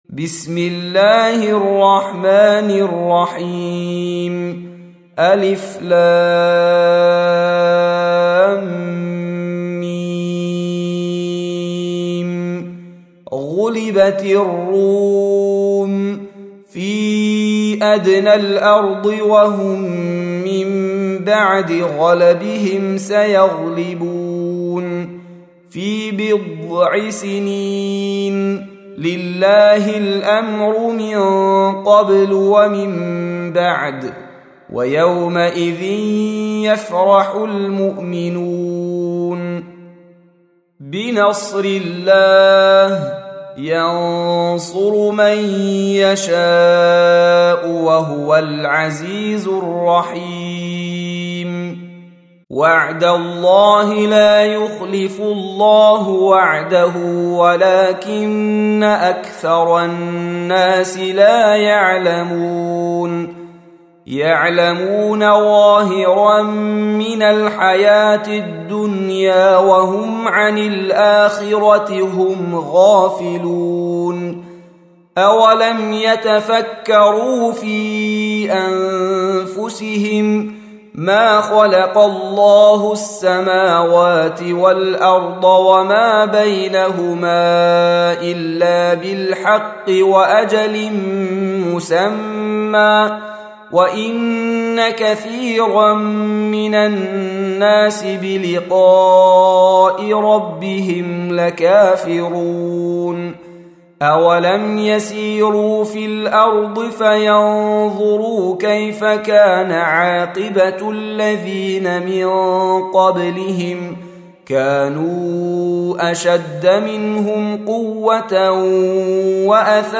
Surah Sequence تتابع السورة Download Surah حمّل السورة Reciting Murattalah Audio for 30. Surah Ar�R�m سورة الرّوم N.B *Surah Includes Al-Basmalah Reciters Sequents تتابع التلاوات Reciters Repeats تكرار التلاوات